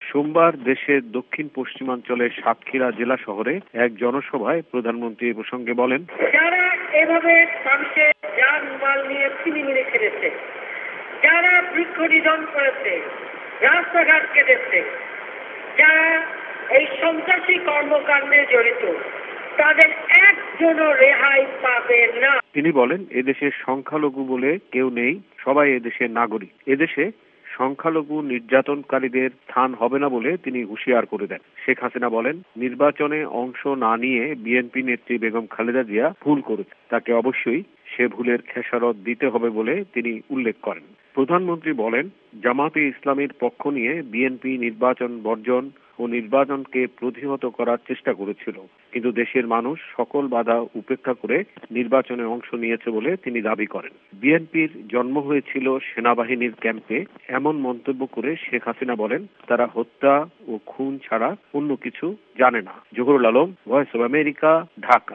khaleda report